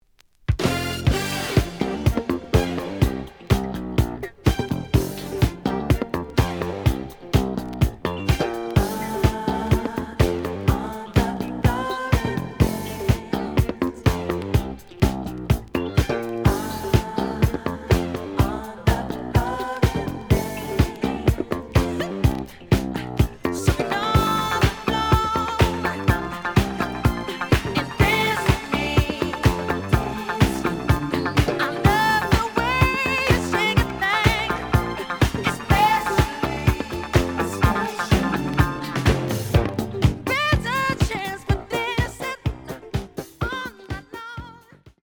試聴は実際のレコードから録音しています。
The audio sample is recorded from the actual item.
●Format: 7 inch
●Genre: Disco